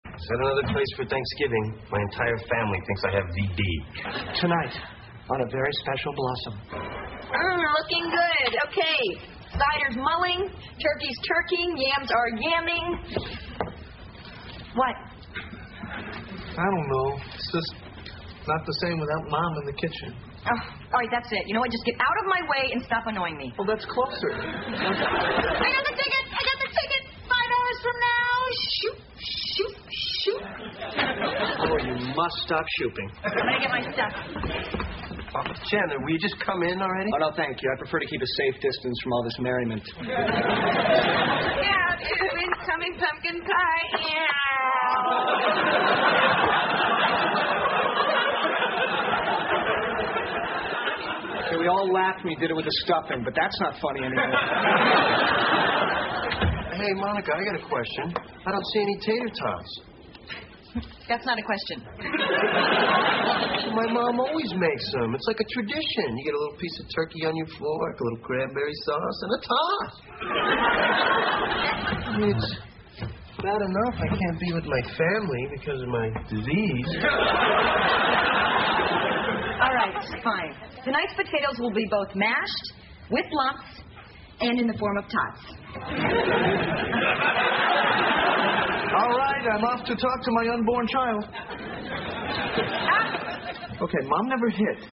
在线英语听力室老友记精校版第1季 第106期:气球飞走了(7)的听力文件下载, 《老友记精校版》是美国乃至全世界最受欢迎的情景喜剧，一共拍摄了10季，以其幽默的对白和与现实生活的贴近吸引了无数的观众，精校版栏目搭配高音质音频与同步双语字幕，是练习提升英语听力水平，积累英语知识的好帮手。